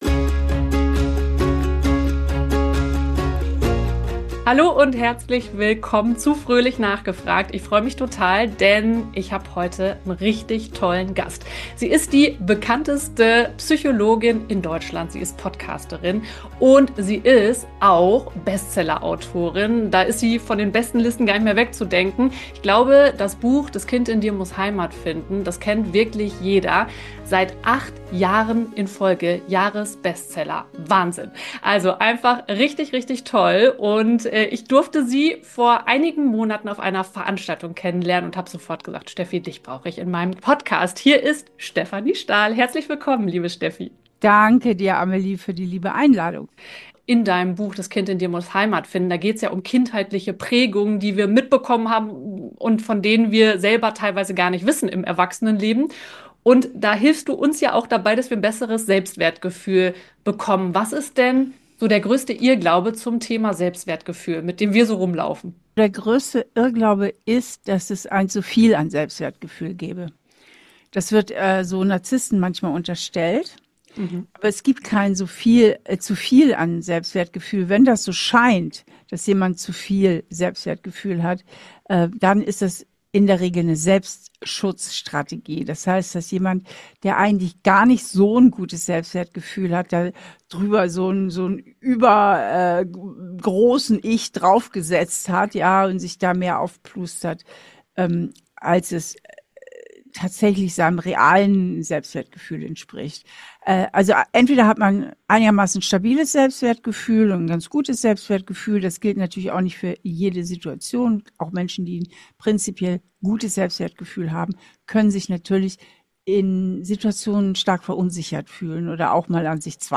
Interview mit Psychologin Stefanie Stahl ~ Fröhlich nachgefragt - Dein Podcast für eine souveräne und authentische Kommunikation Podcast